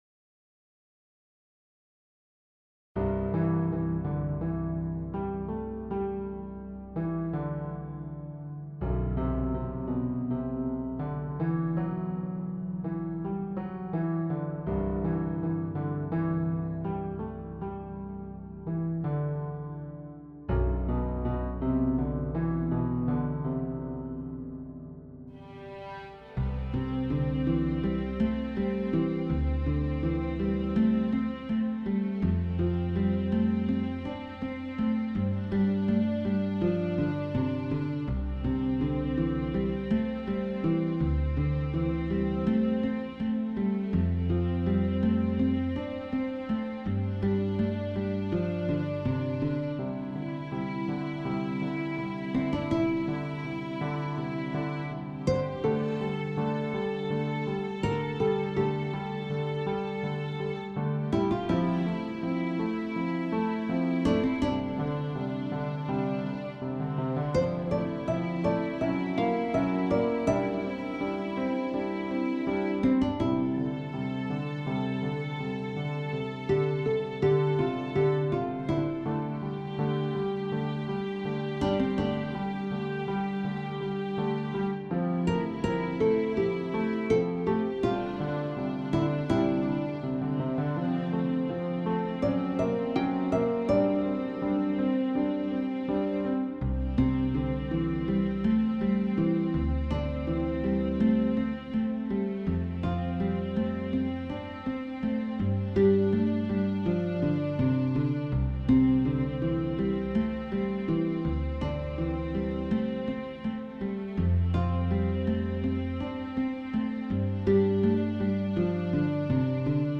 BGM
ロング暗い